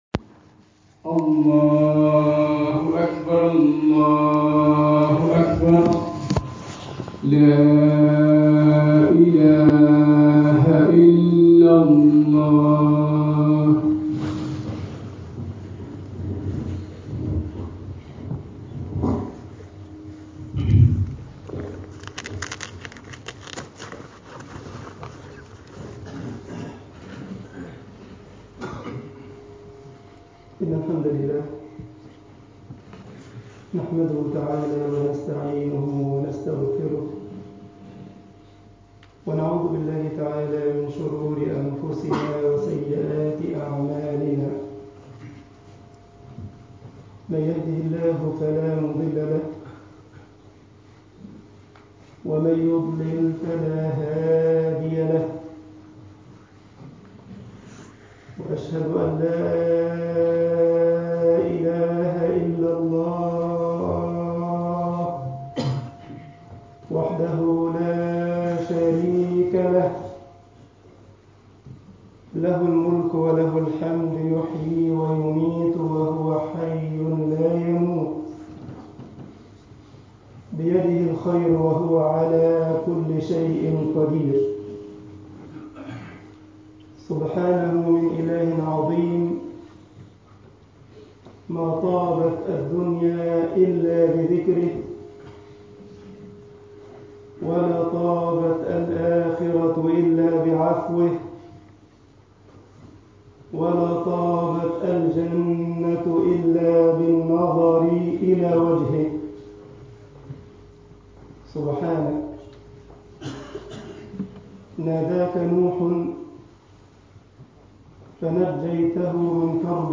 Freitagsgebet_al esmat min fetnat al-ilm3.mp3